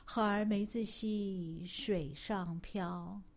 Chinese Nursery Rhyme